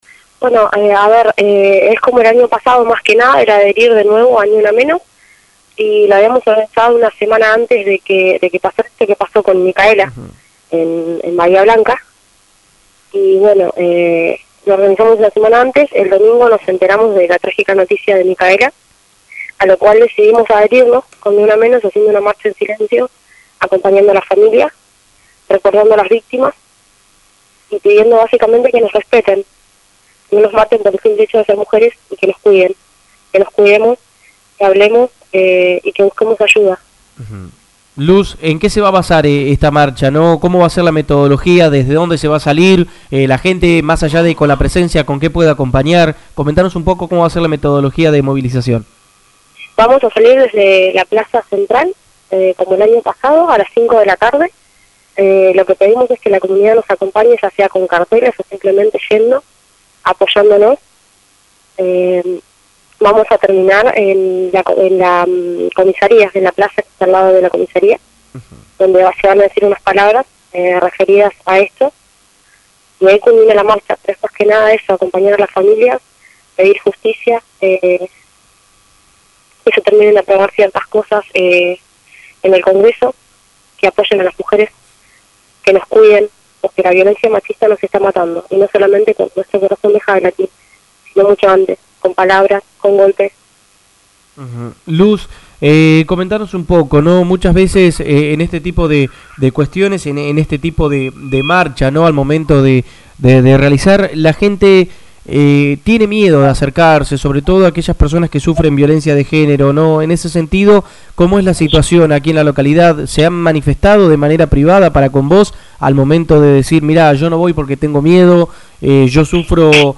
paso por los micrófonos de la Zfm y esto decía al Respecto.